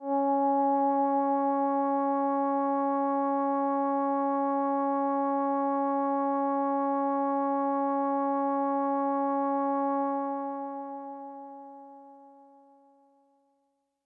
描述：通过Modular Sample从模拟合成器采样的单音。
标签： MIDI-速度-96 CSharp5 MIDI音符-73 罗兰木星-4 合成器 单票据 多重采样
声道立体声